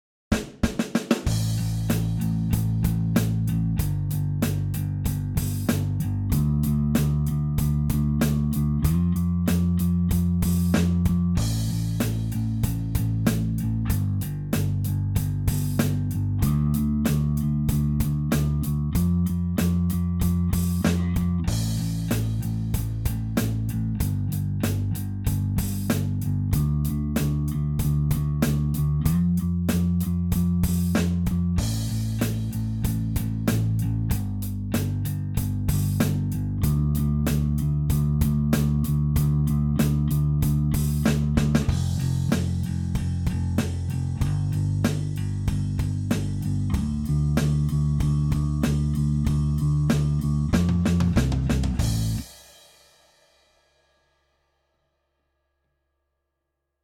The Fake Drums Tone Thread
Here's a fake drums test: I've left a bassline on for a bit of context.
Might need a little less room/ambiance on, the snare, & bring it up just a little on everything else so it sounds more like a drum set in a room....Just my opinion dude, but lemme know what you're using for these drums.....